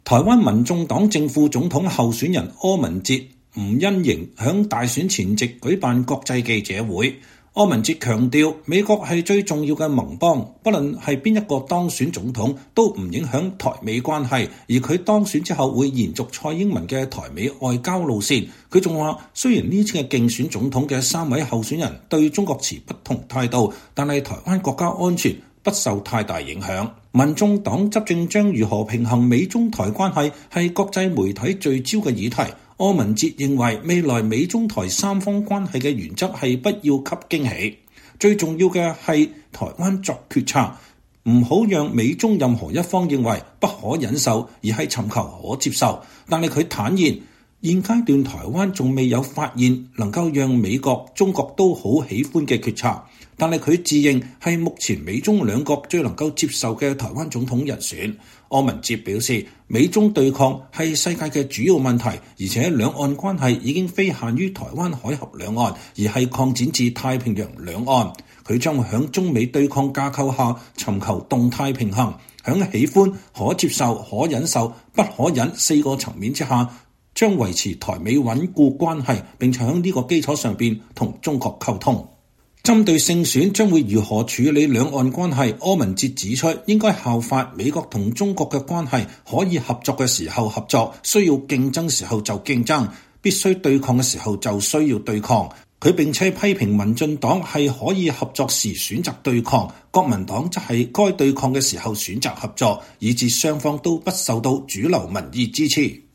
民眾黨選前國際記者會柯文哲：美中台關係“不要有驚喜”